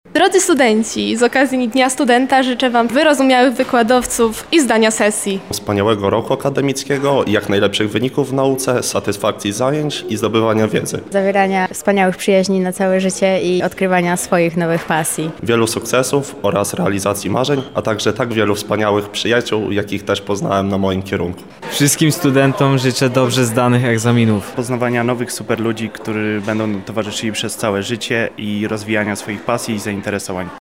Zapytaliśmy lubelskich studentów, czego życzą swoim kolegom:
SONDA